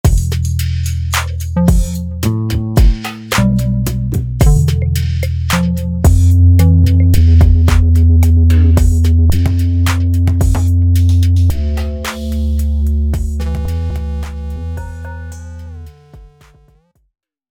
Download Bass sound effect for free.
Bass